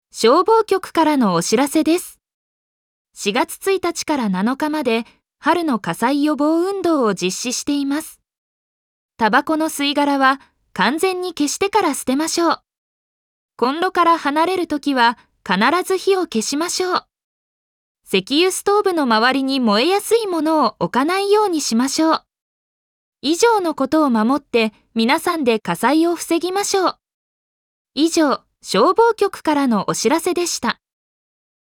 本音声データは、当運動期間中の館内放送のための音声データとなります。